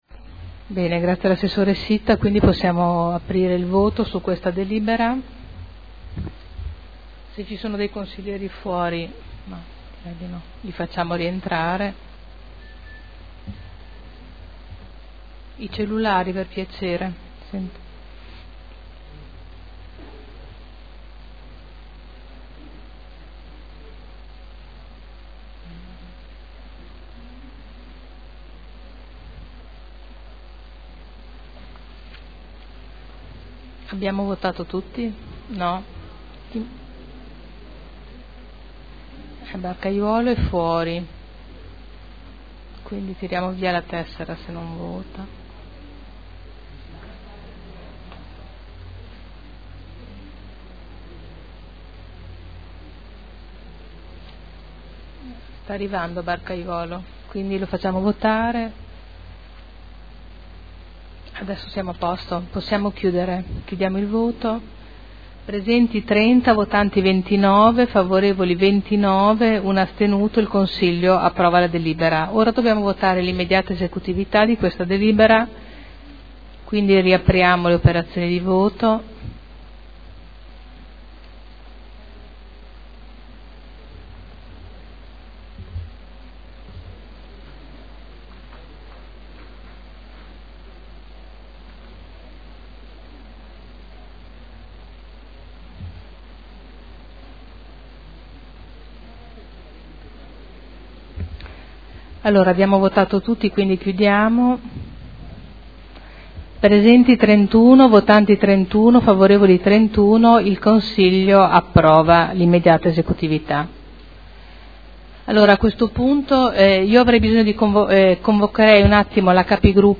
Presidente — Sito Audio Consiglio Comunale
Il Presidente Caterina Liotti mette ai voti la proposta di variante al POC per la pianificazione di un tracciato di linea elettrica interrata a 15 KV denominato cavo Maserati – Parere favorevole (Commissione consiliare del 17 maggio 2011).